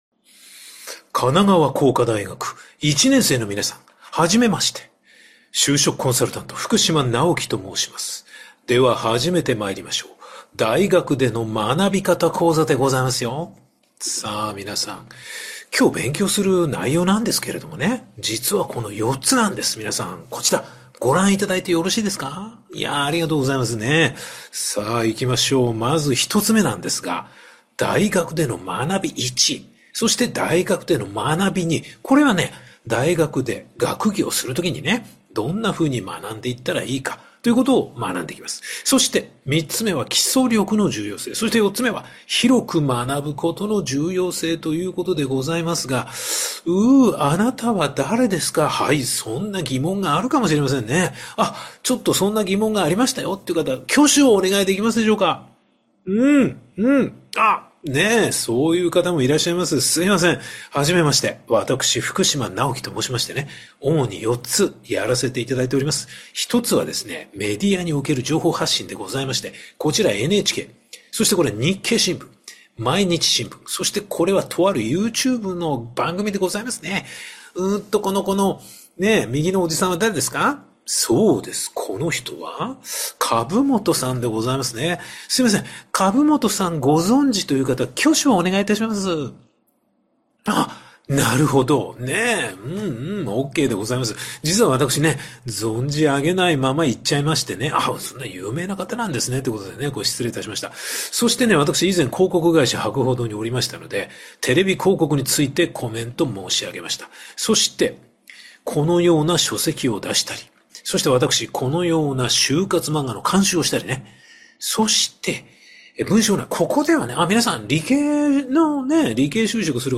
1hのテスト | Lecture Transcriber